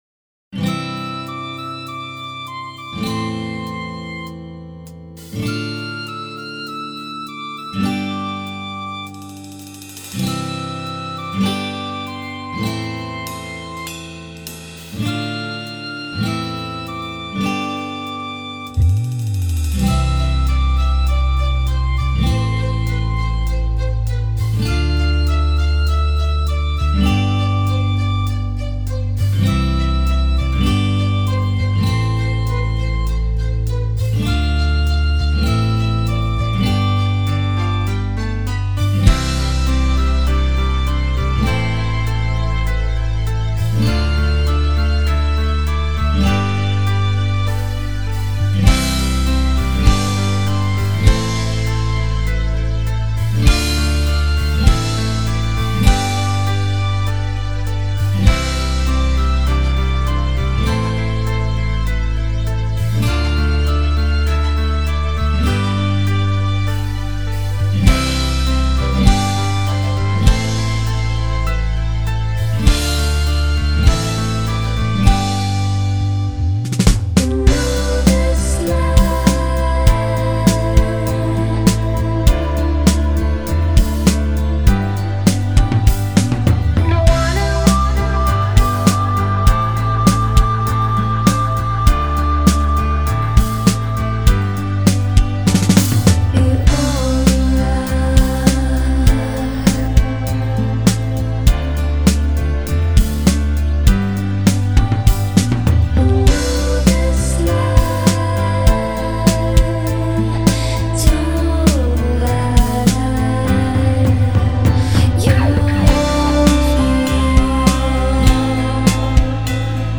Стиль: Instrumental